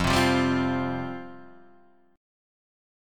Fsus2 chord {1 3 3 0 1 1} chord